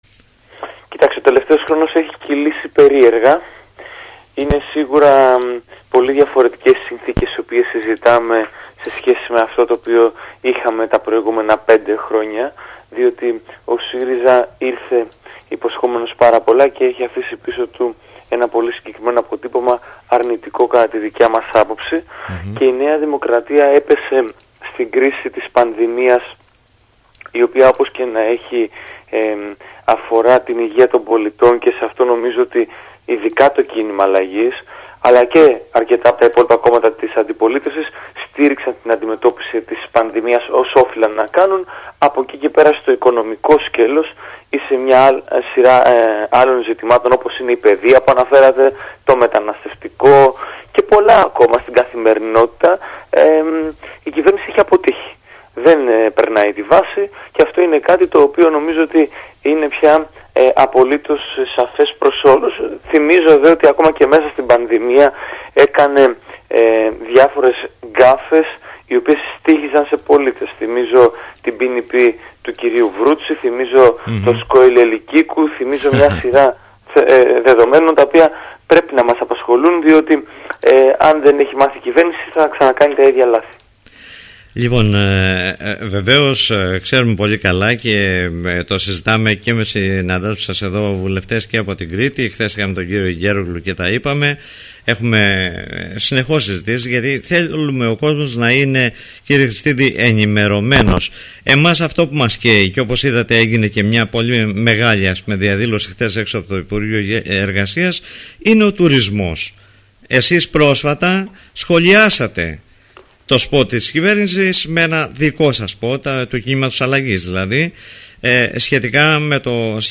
Για μια σειρά θεμάτων, που αφορούν και στον τουρισμό, μίλησε στον Politica 89.8